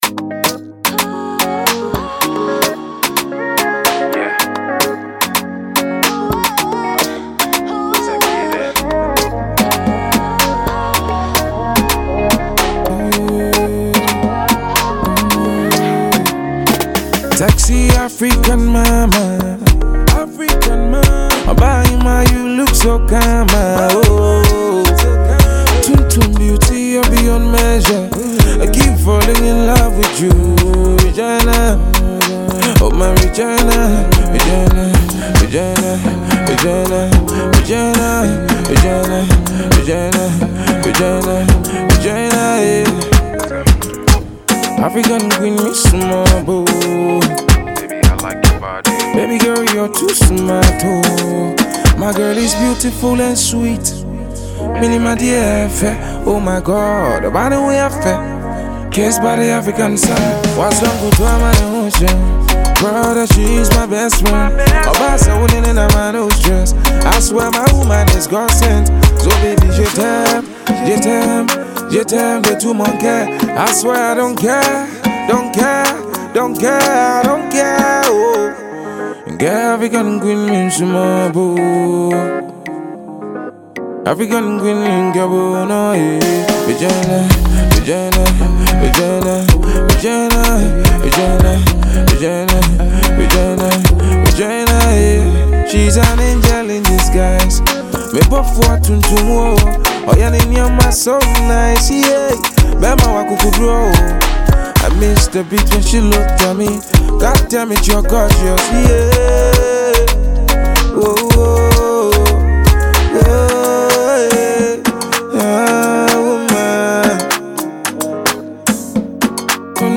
vintage Ghana music flavor